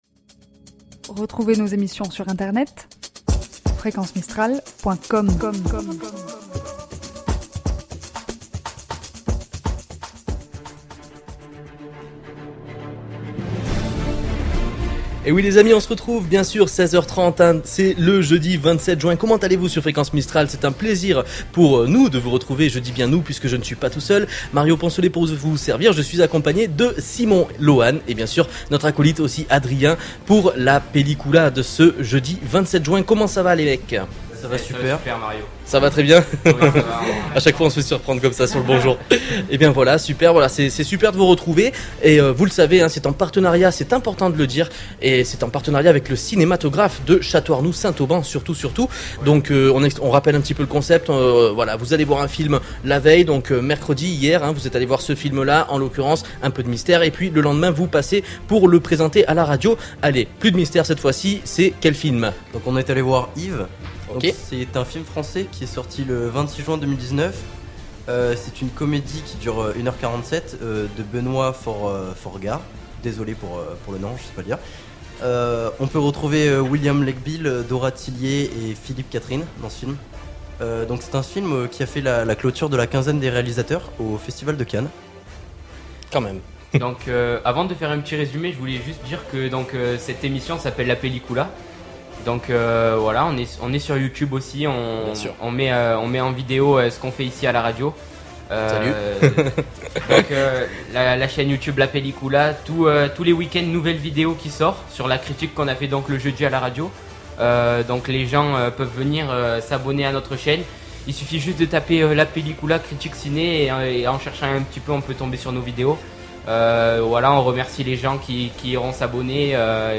Des films visionnés chaque semaine puis notés par ces derniers pour un projet avec notre partenaire le Cinématographe de Château-Arnoux, auquel se joint avec plaisir Fréquence Mistral, afin de vous tenir informés sur les sorties ciné tous les jeudis en direct à 16h30 !